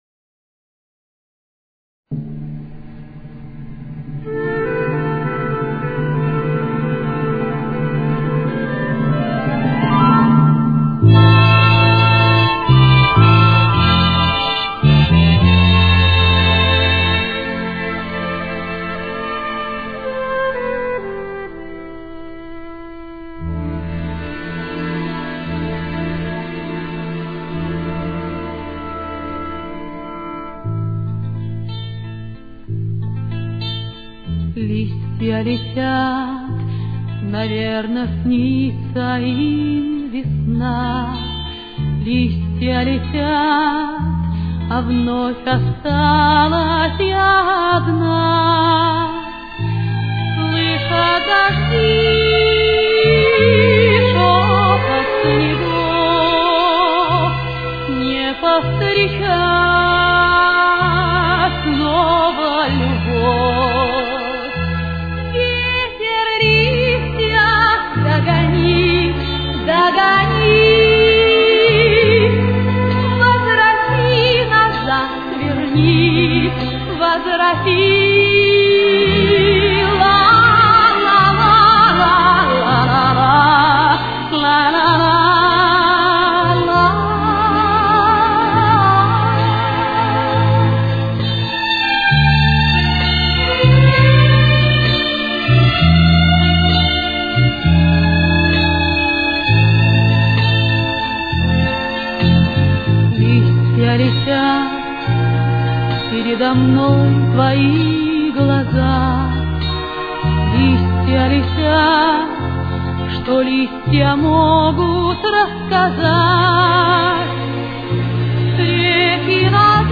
с очень низким качеством (16 – 32 кБит/с)
Темп: 122.